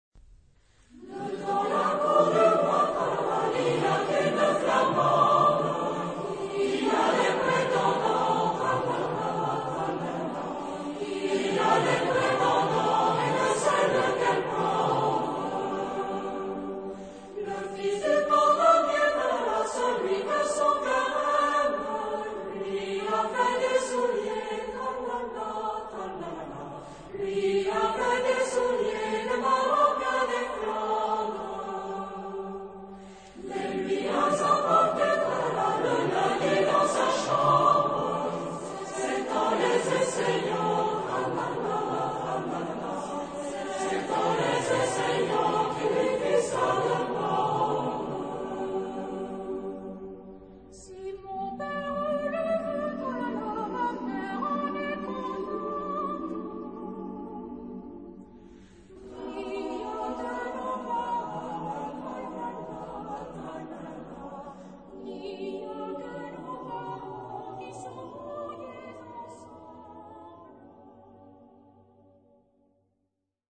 Genre-Style-Form: Popular ; Secular
Mood of the piece: joyous
Type of Choir: SATB  (4 mixed voices )
Tonality: G mixolydian